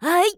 YX蓄力4.wav 0:00.00 0:00.38 YX蓄力4.wav WAV · 33 KB · 單聲道 (1ch) 下载文件 本站所有音效均采用 CC0 授权 ，可免费用于商业与个人项目，无需署名。
人声采集素材